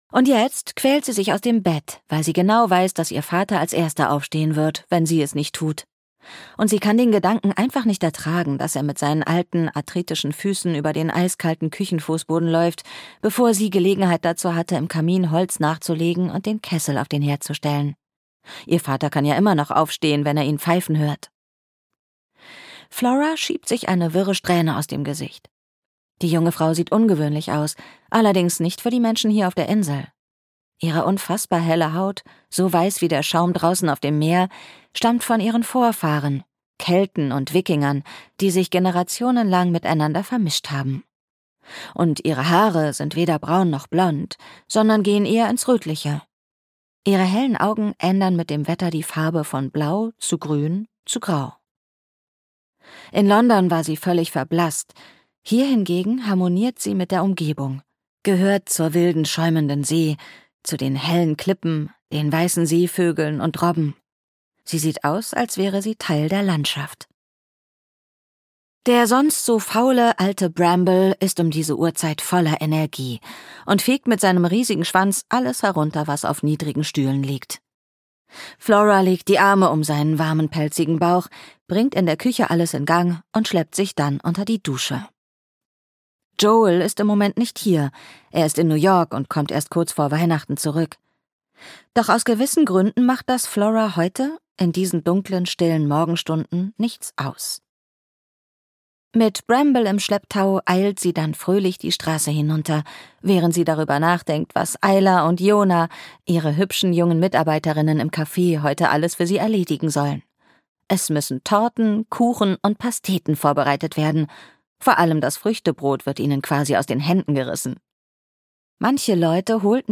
Weihnachten in der kleinen Sommerküche am Meer (Floras Küche 3) - Jenny Colgan - Hörbuch